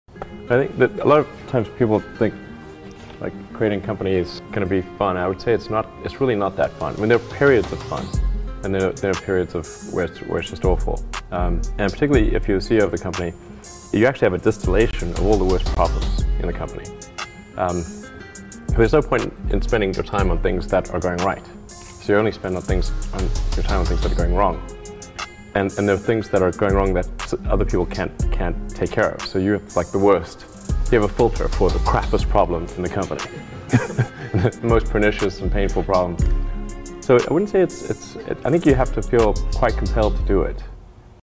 Billionaire's Mindset Elon Musk Motivational Best Motivational Speech.